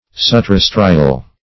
Subterrestrial \Sub`ter*res"tri*al\, a.
subterrestrial.mp3